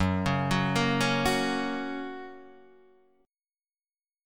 F# chord